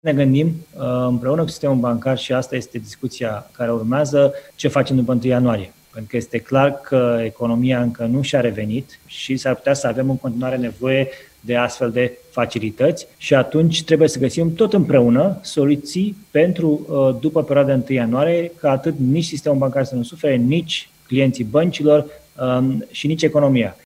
Amânarea plății ratelor la credite ar putea fi prelungită și după 1 ianuarie, este varianta pe care a anunțat-o joi dimineață ministrul Finanțelor în cadrul unei conferințe de specialitate.